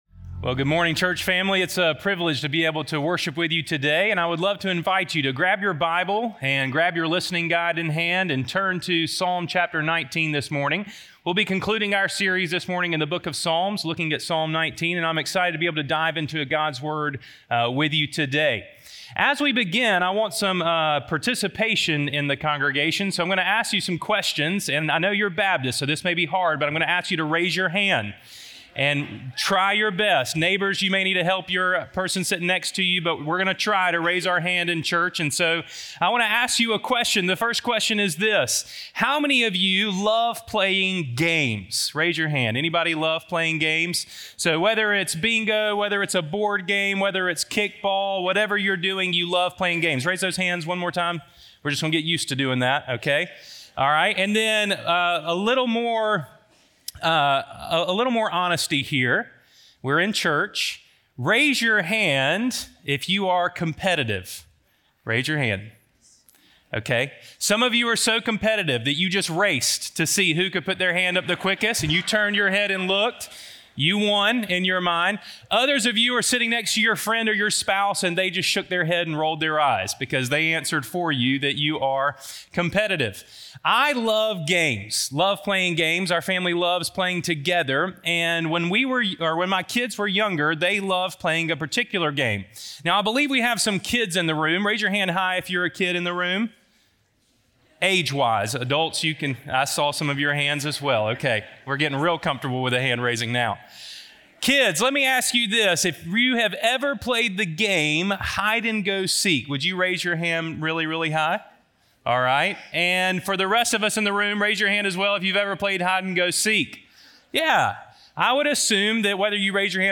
The Revelation of God - Sermon - Ingleside Baptist Church